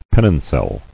[pen·non·cel]